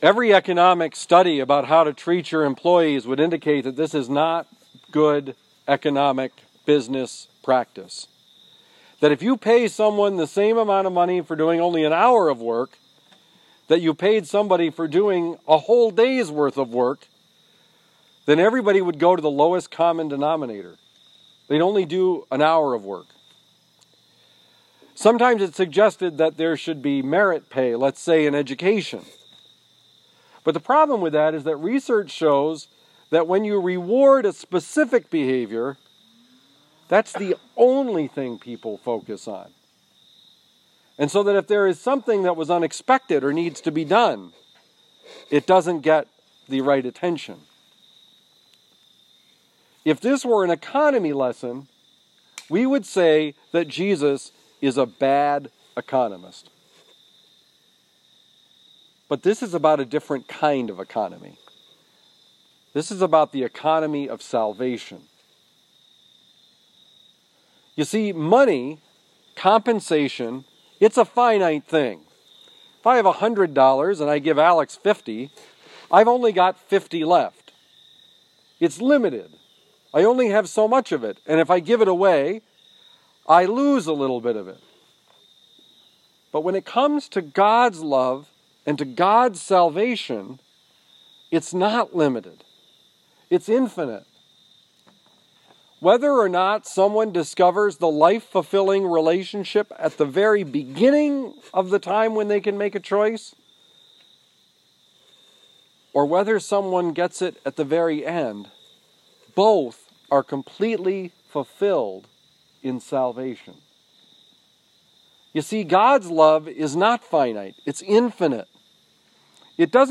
Brothers in Prayer Fall Retreat Homily
Given at Camp Trinity in New Haven, Missouri